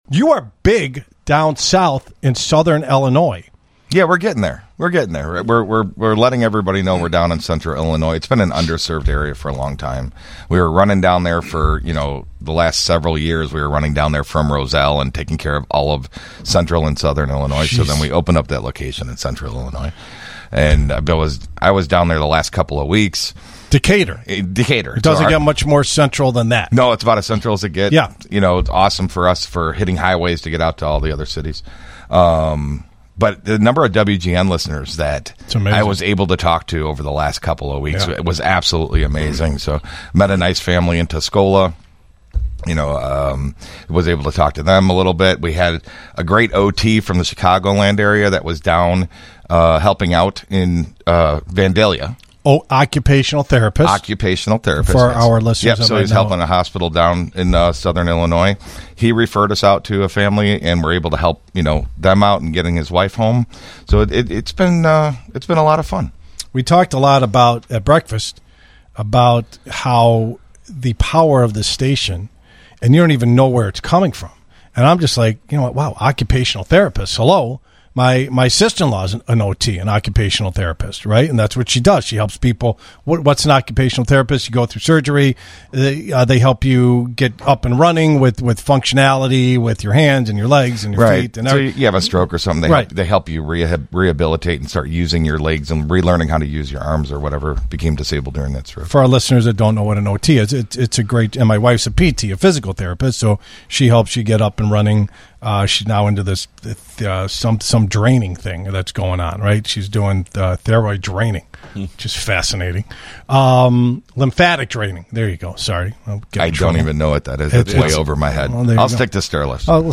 Featured on WGN Radio’s Home Sweet Home Chicago on 10/04/25